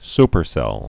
(spər-sĕl)